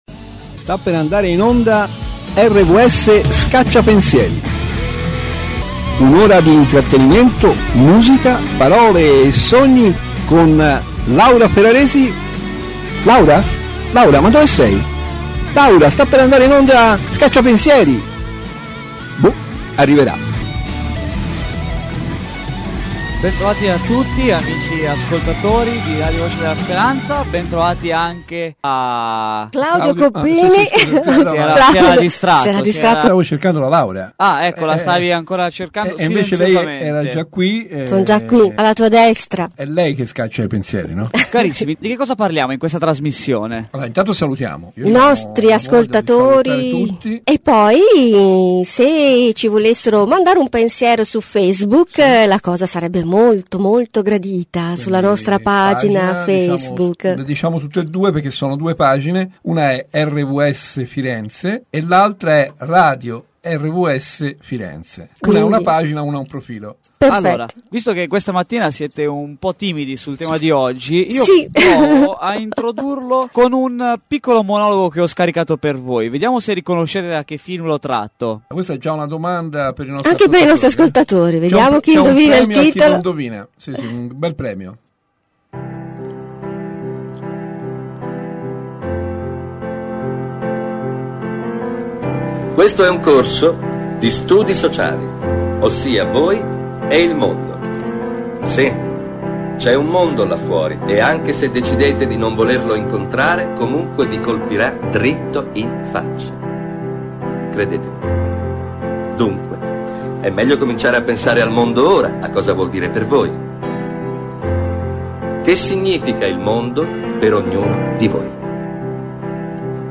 Un programma ricco di musica, parole, pensieri, belle notizie.